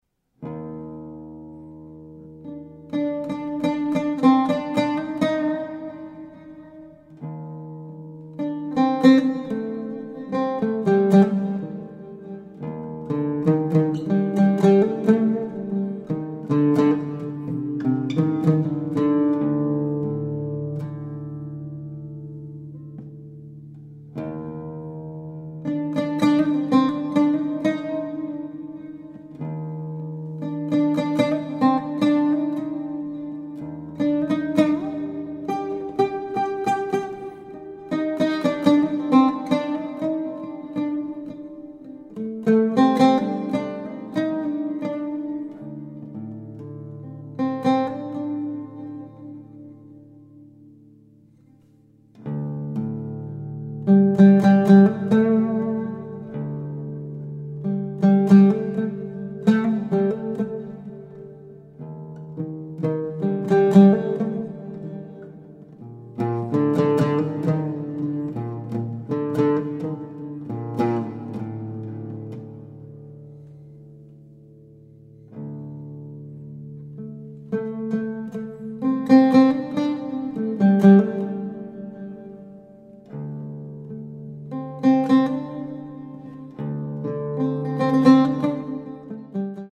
Japanese Ambience , Oud